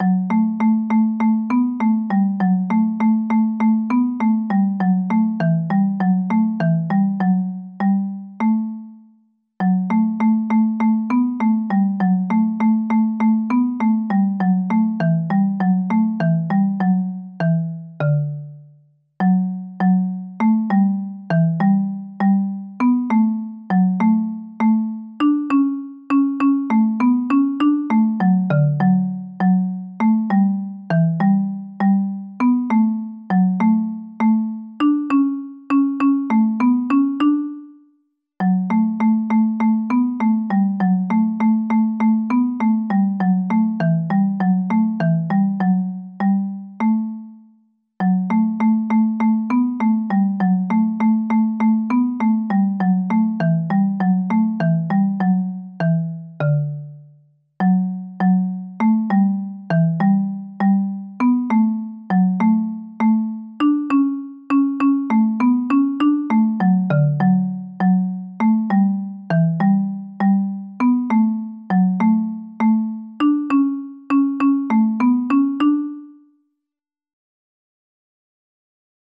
Jewish Folk Song for Pesach (Passover)
D major ♩= 100 bpm